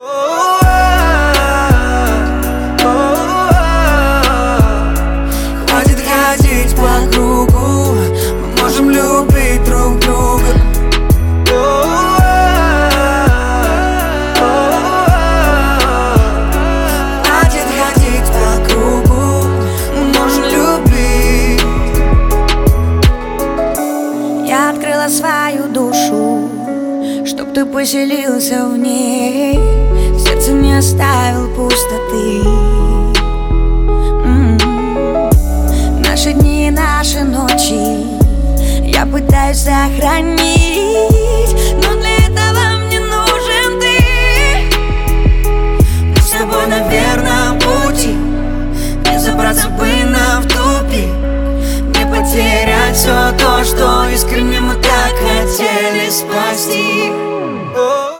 • Качество: 128, Stereo
поп
рэп
дуэт